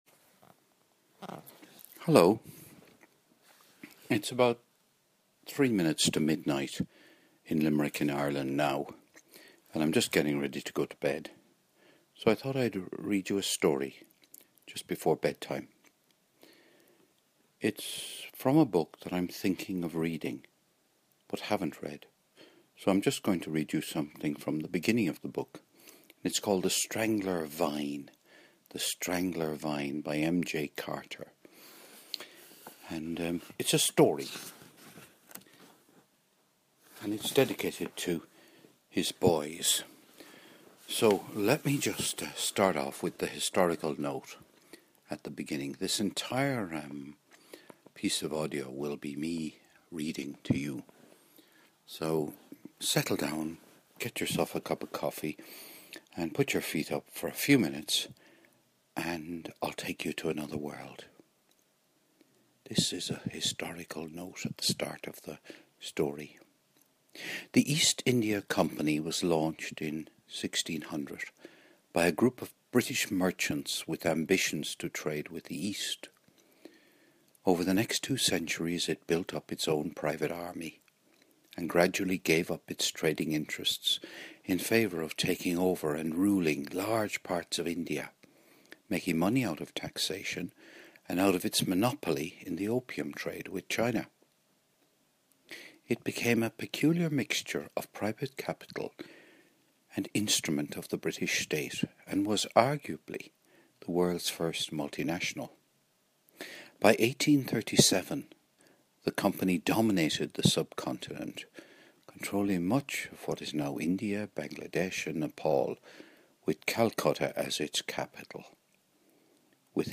Bedtime Story "The Strangler Vine" by M J Carter